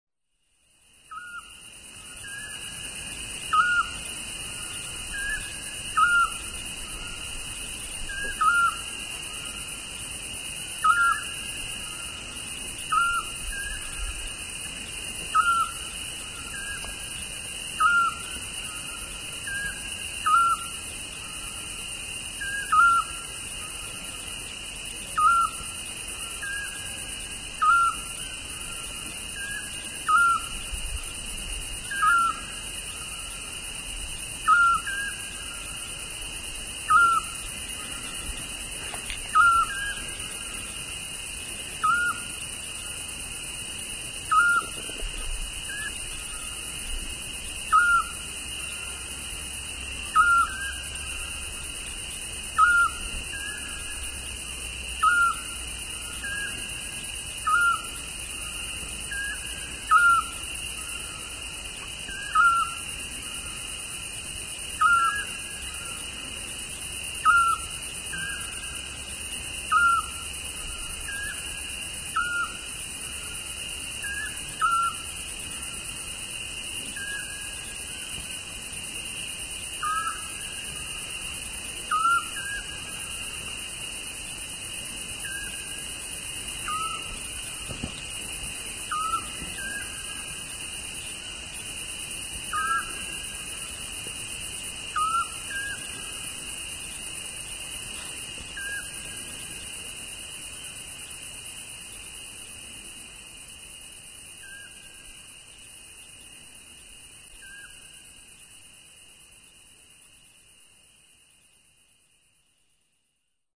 Field recording, 29/08/2004, 21:10h, Vikos-Canyon/ Greece
This compilation combines raw, uncut and cut field recordings with electronic, instrumental and material arrangements.